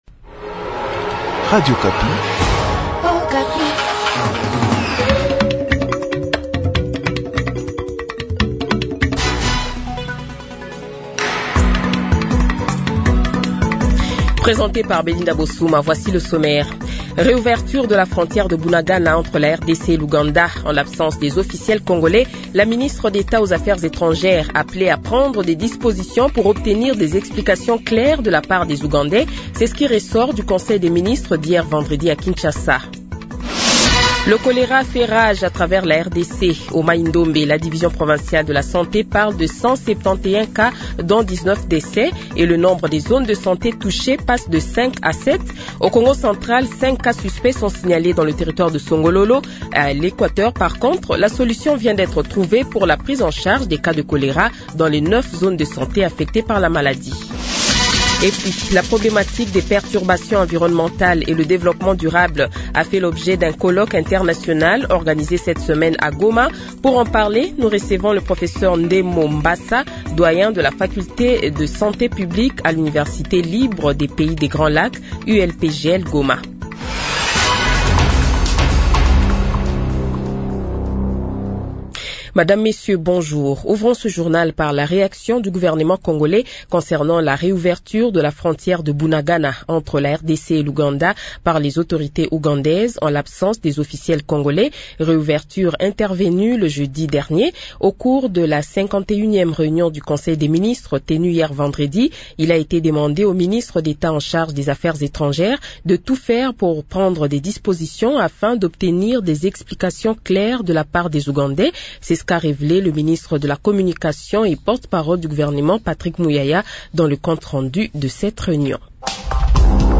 Journal Francais Midi
Le Journal de 12h, 12 Juillet 2025 :